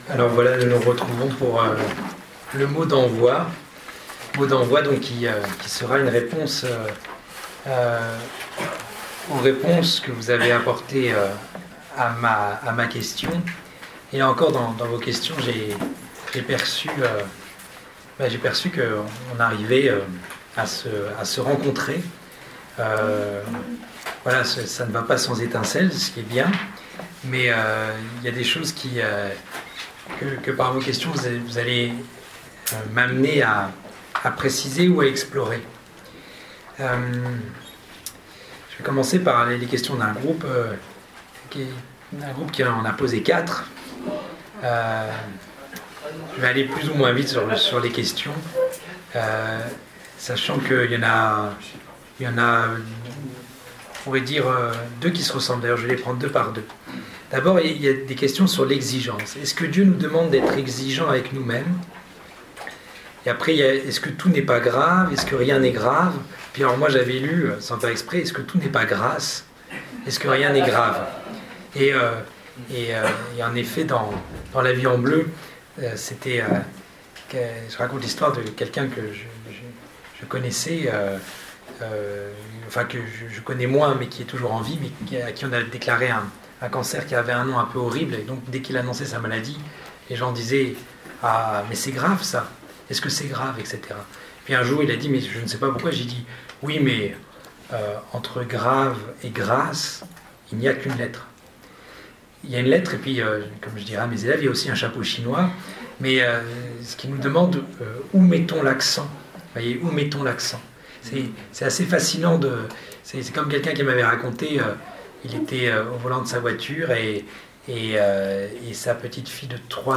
» Week-end spirituel des 23- et 24 février 2019, à Trosly-Breuil (Oise) 1.